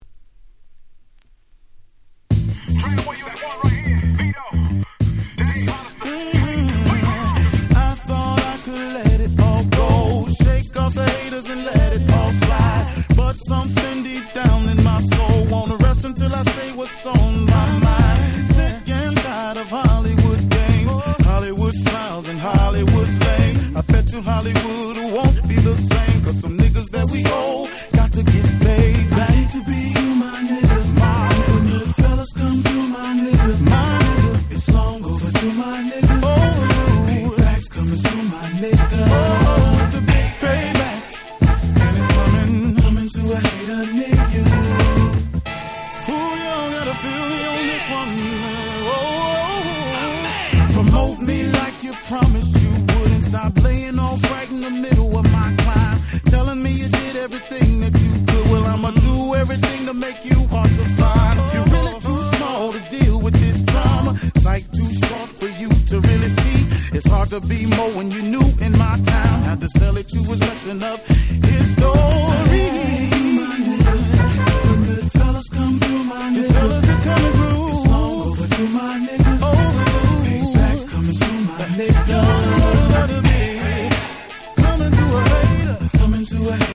03' Very Nice R&B EP !!